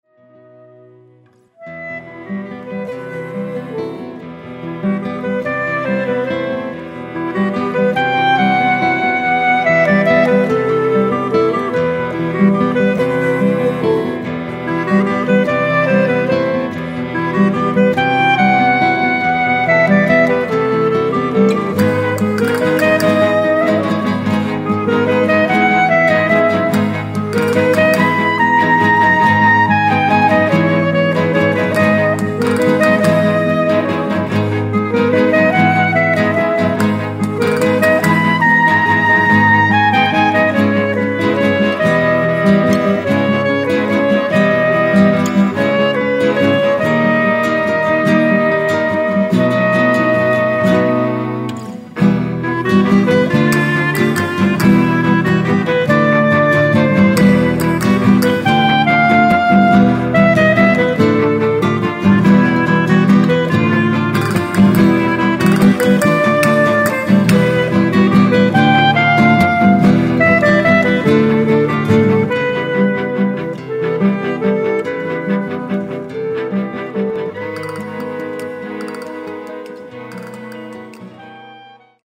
und Computersounds, eingängig und atmosphärisch dicht.
Viola
Cello